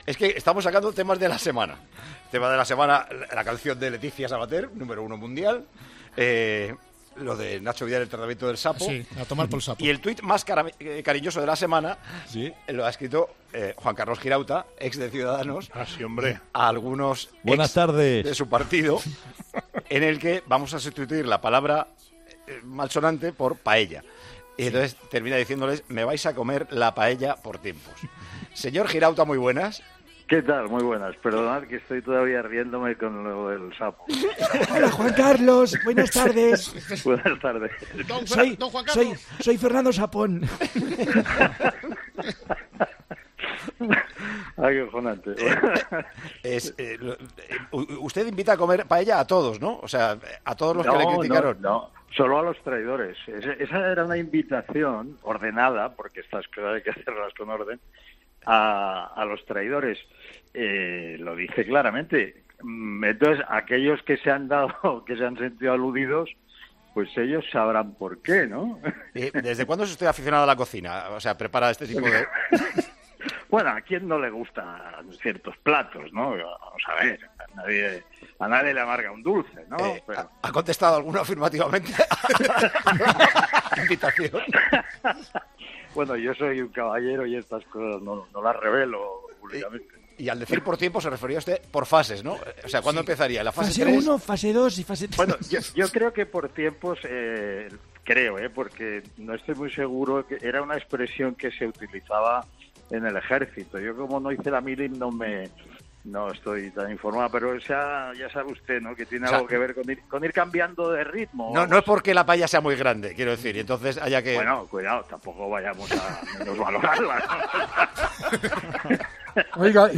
El ex diputado de Ciudadanos, Juan Carlos Girauta, explica en Tiempo de Juego a quién iban dirigidos sus mensajes “malsonantes” en redes sociales